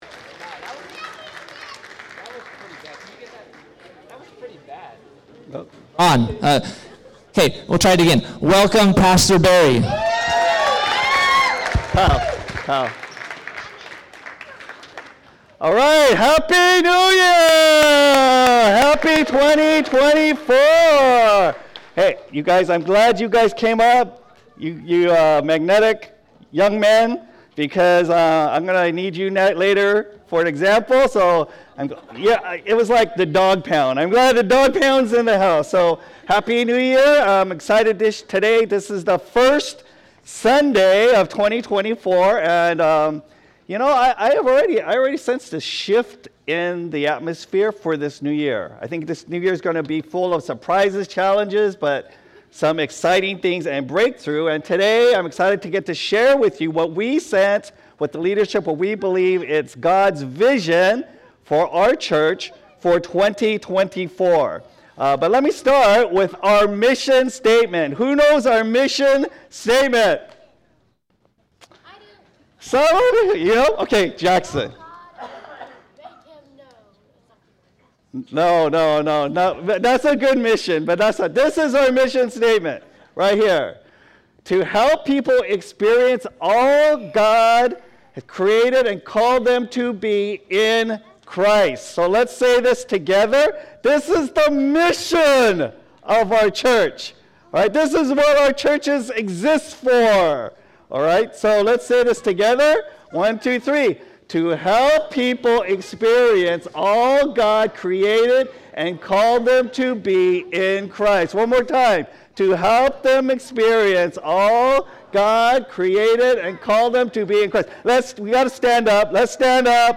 Sermons | Catalyst Christian Community